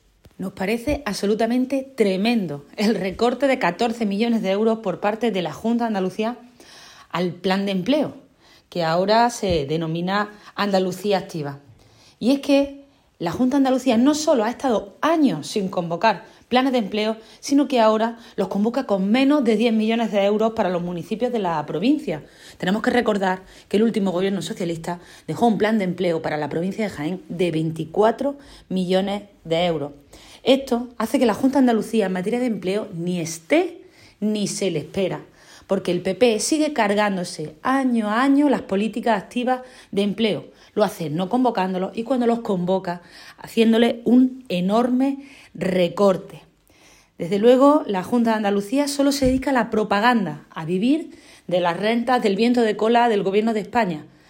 Cortes de sonido
Mercedes-Gamez-plan-empleo-Junta.mp3